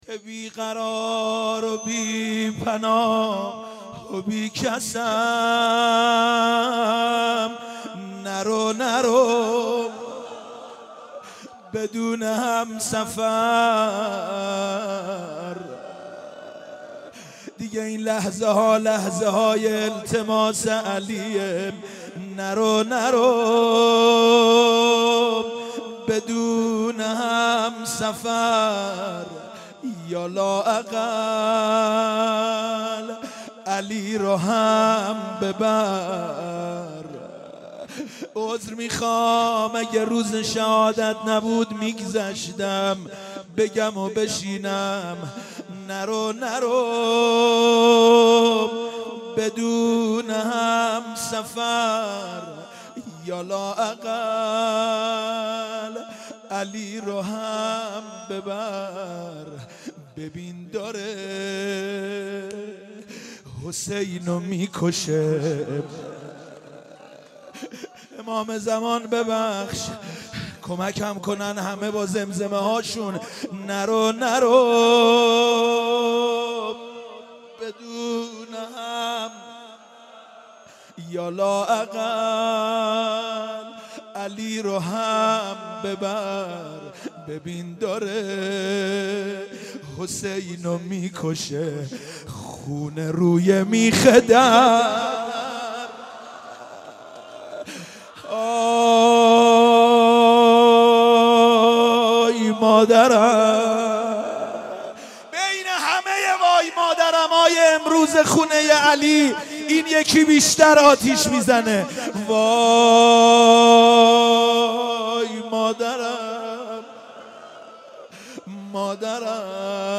دانلود مداحی همسفر من - دانلود ریمیکس و آهنگ جدید